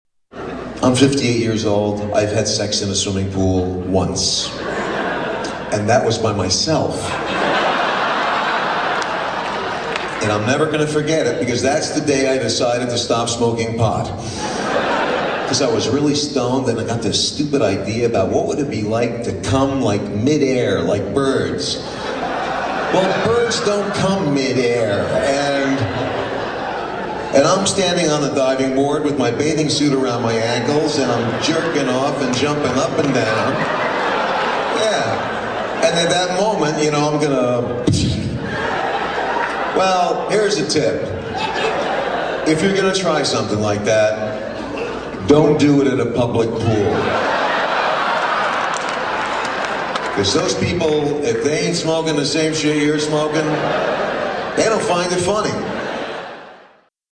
Category: Comedians   Right: Personal
Tags: Comedian Robert Schimmel clips Robert Schimmel audio Stand-up comedian Robert Schimmel